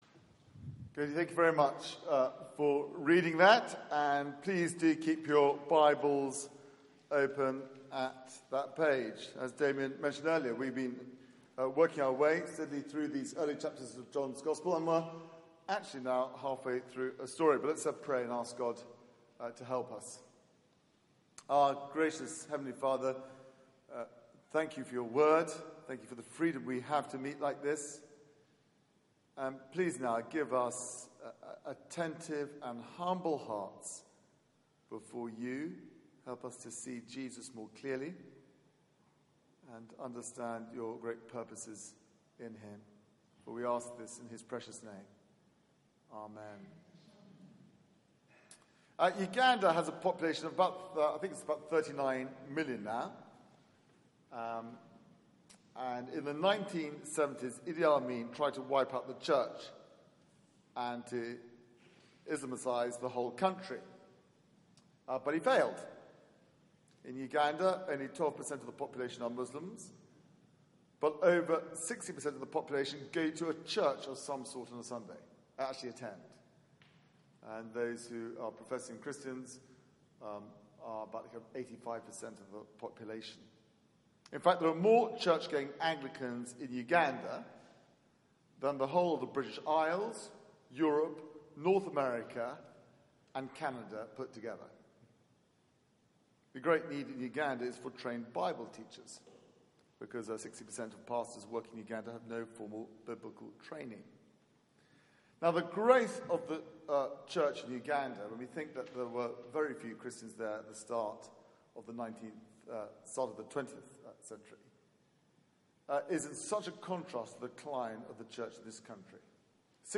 Media for 4pm Service on Sun 24th Jul 2016 16:00 Speaker
This is Jesus Theme: Saviour of the World Sermon Search the media library There are recordings here going back several years.